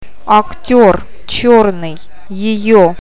O ääntyy painottomana a :n kaltaisena, e ja я i :n tai ji :n tapaisena äänteenä.
Paino eri tavulla saa aikaan merkityseron: